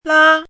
chinese-voice - 汉字语音库
la1.mp3